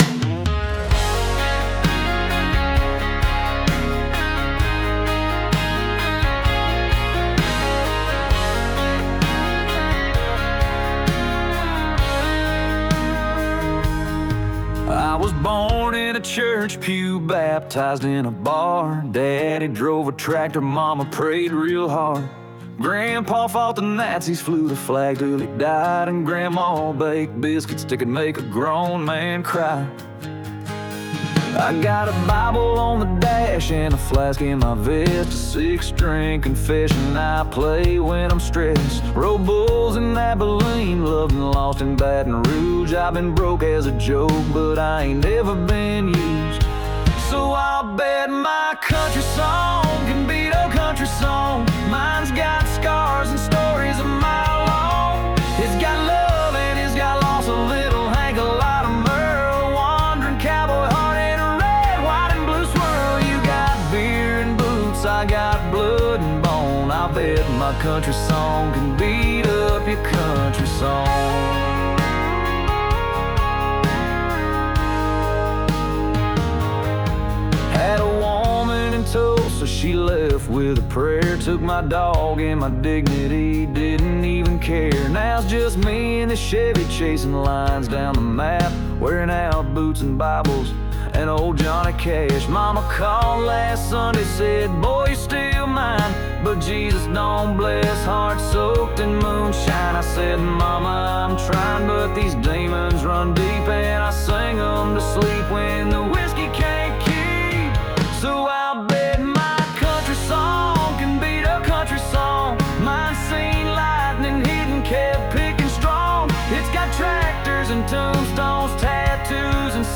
Country Songs – Demo Versions